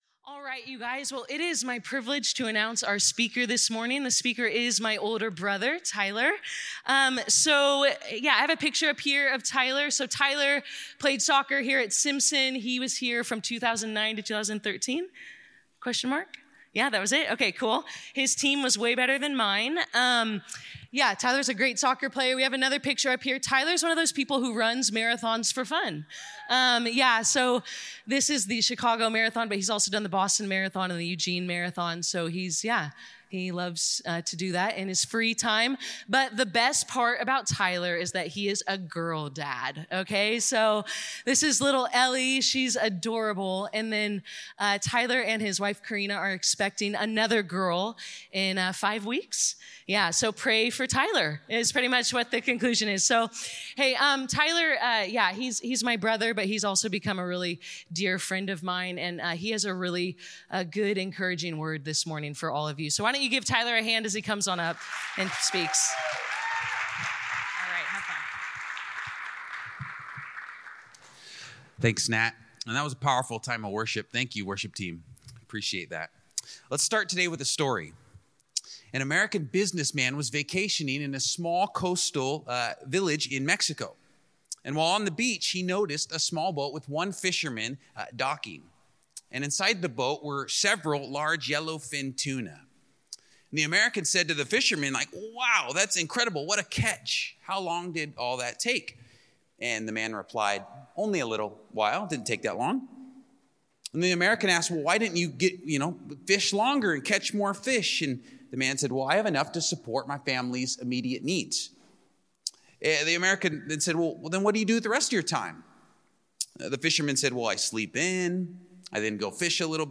This talk was given in chapel on Wednesday, January 29, 2025 God Bless you.